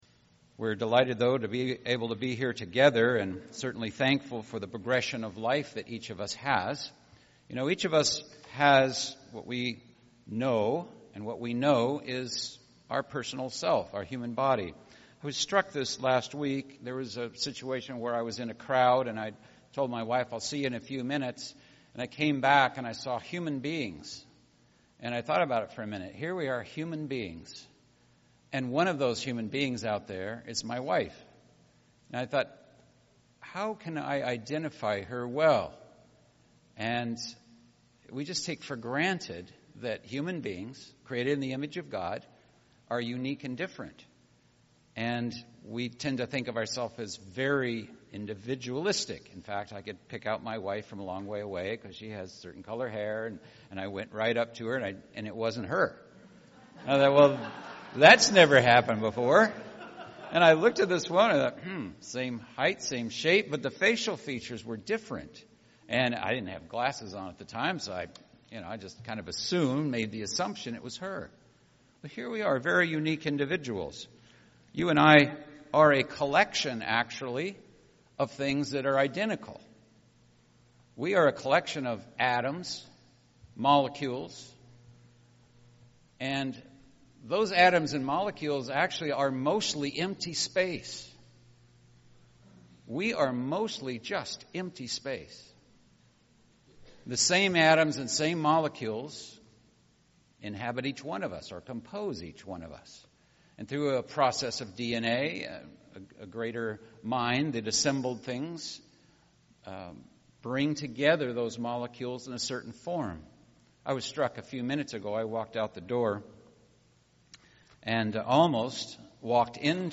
This sermon examines how to receive it and how to let it lead your life in developing Godliness.